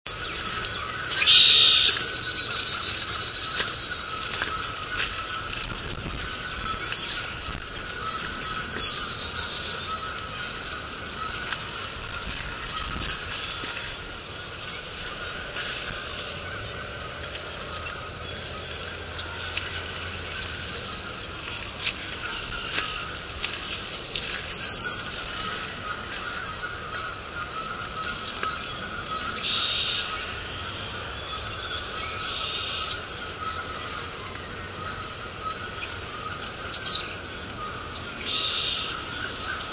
Carolina Chickadee